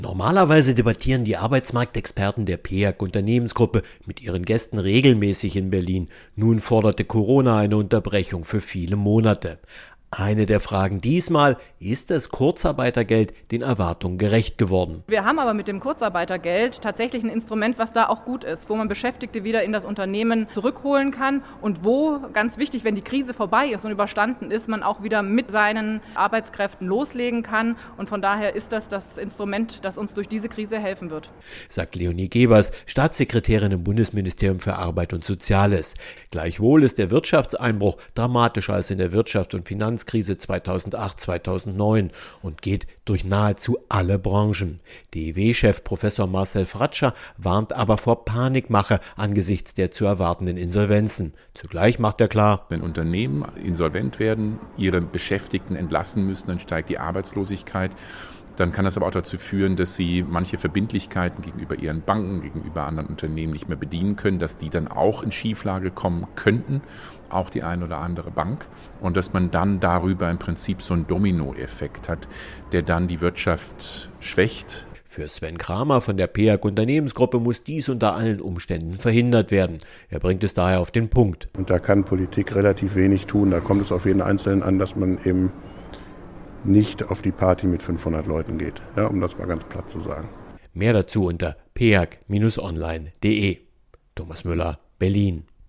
O-Töne / Radiobeiträge, Politik, , , , ,